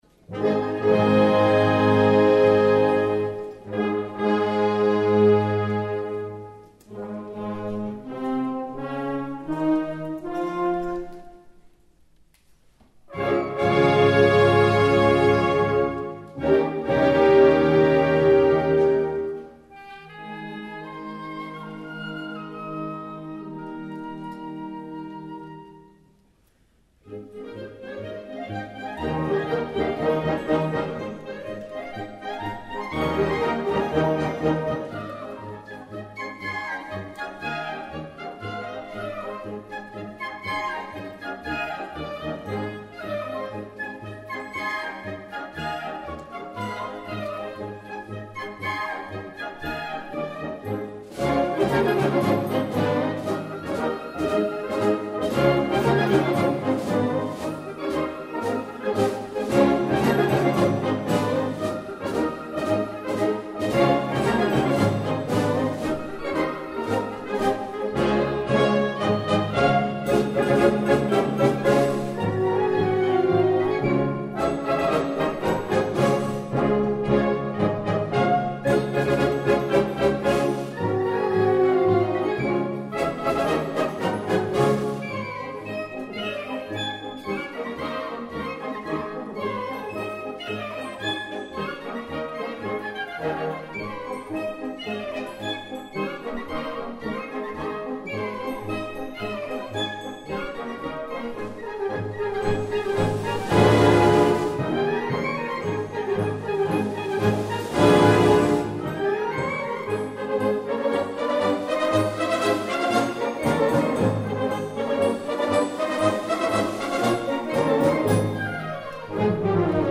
Catégorie Harmonie/Fanfare/Brass-band
Sous-catégorie Galop
Instrumentation Ha (orchestre d'harmonie)